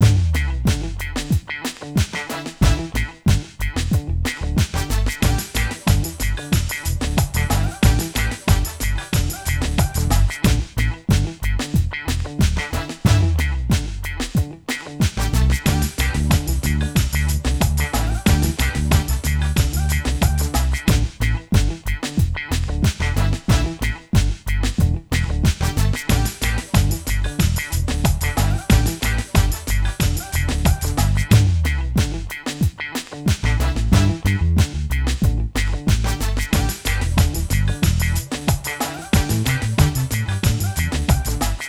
Soul funk 2 (bucle)
funk
melodía
repetitivo
rítmico
sintetizador
soul